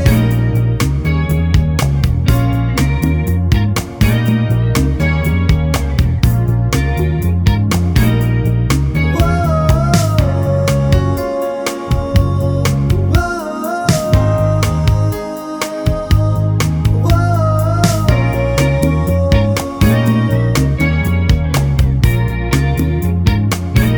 no Sax Pop (1980s) 5:13 Buy £1.50